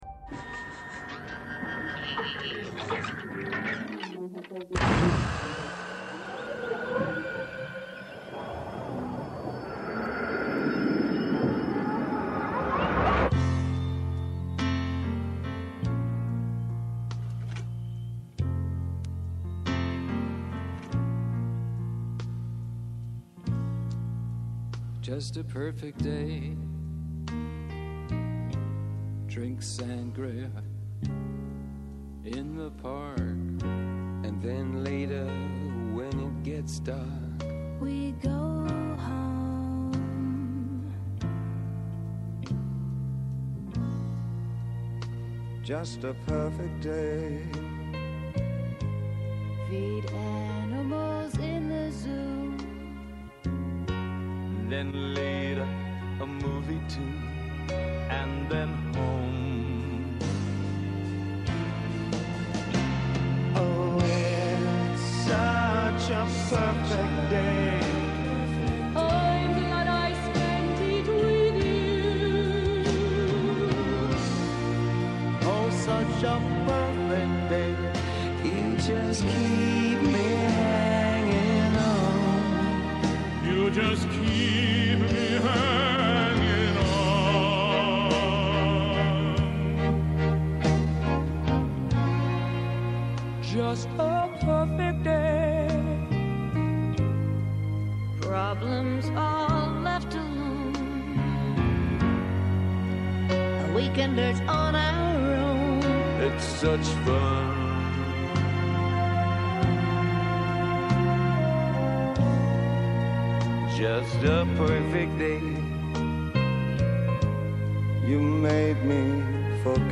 Απόψε ακούμε το σάουντρακ